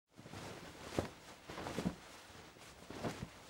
cloth_sail1.R.wav